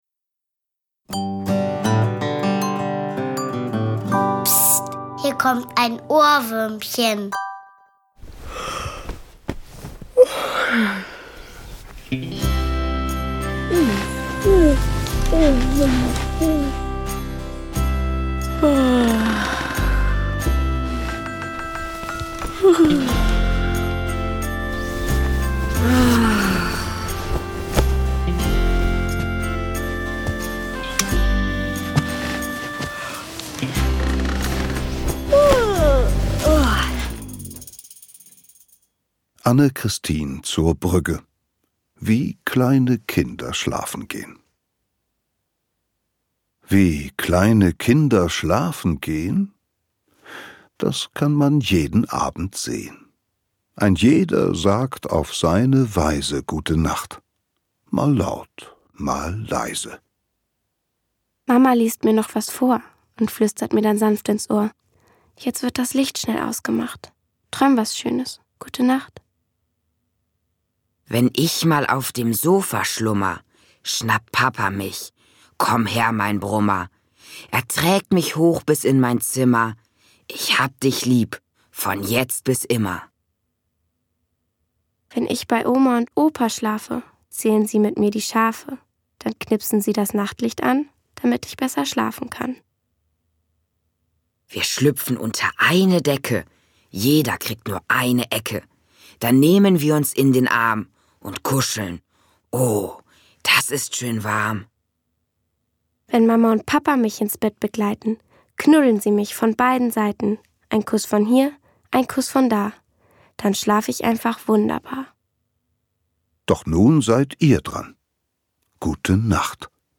Sieben stimmungsvolle Schlaflieder runden die Geschichtensammlung ab.